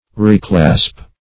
Reclasp \Re*clasp"\ (r[=e]*kl[.a]sp"), v. i. To clasp or unite again.